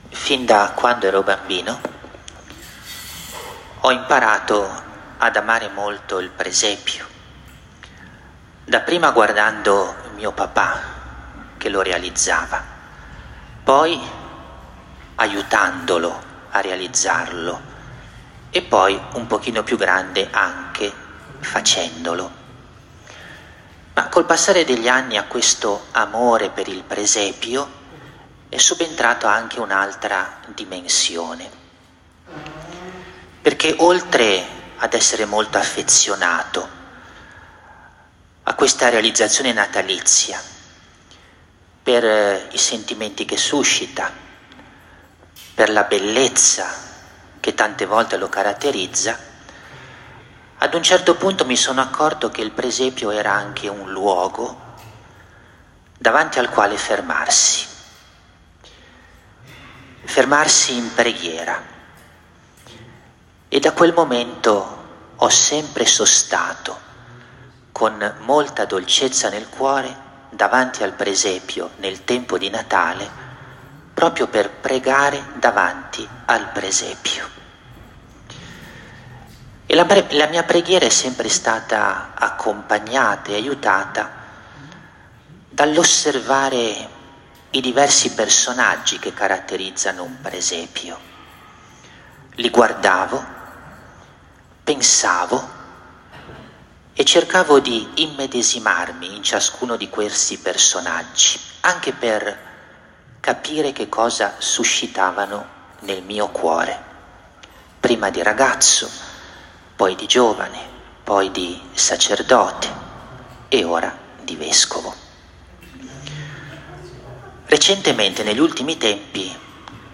ASCOLTA L’OMELIA (mons. Marini)
2026_gen_6_AUDIO_predica-marini_epifania_mdg.m4a